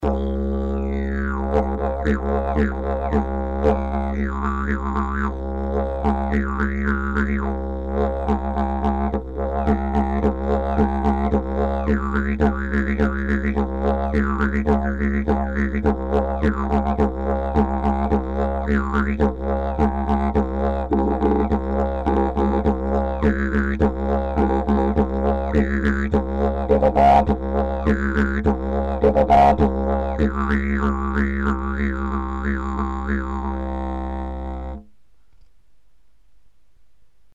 Ich habe mir ein paar Tage Zeit genommen, um die Didjbox zu spielen - zu allen möglichen Gelegenheiten: zu Hause und unterwegs, beim Autofahren im Stop and Go..., und ich habe sogar einen spontanen kleinen Mini-Workshop gegeben und damit zwei halbwüchsige Mädchen für's Didge begeistert ;-)